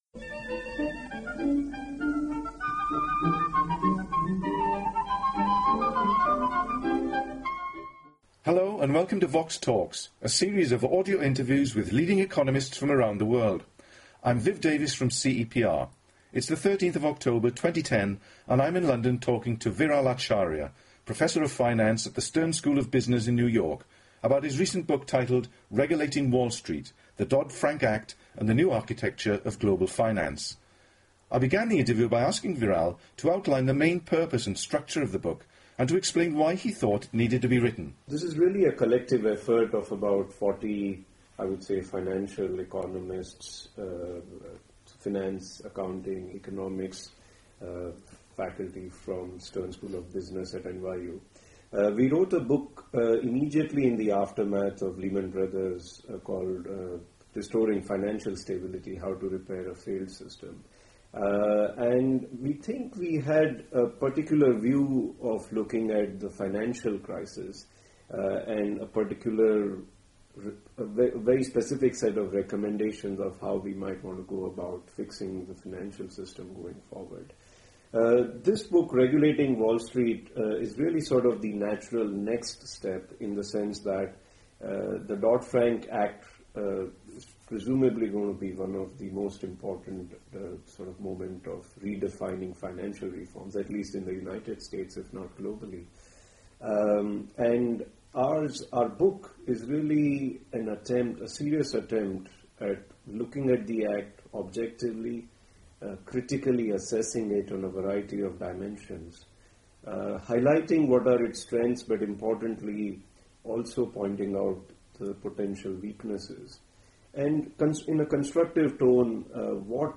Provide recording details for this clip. The interview was recorded in London on 13 October 2010.